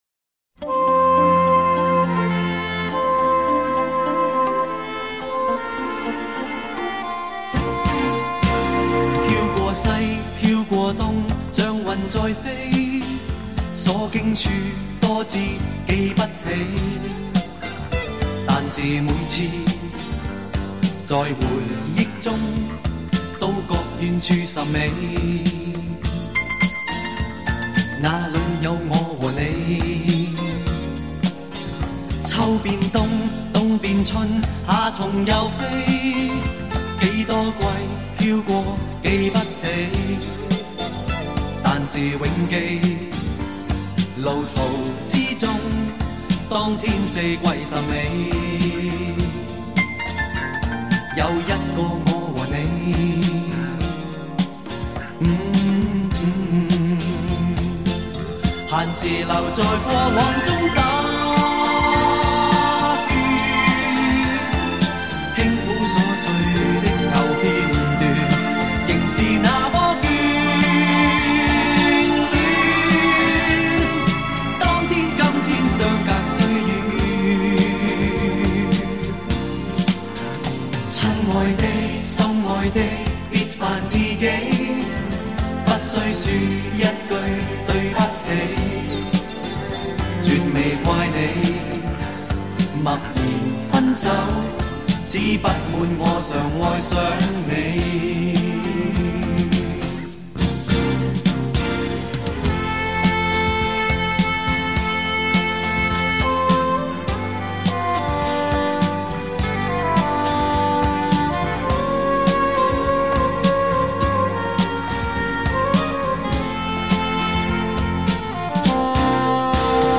体现了早期CD模拟录音暖而厚的特点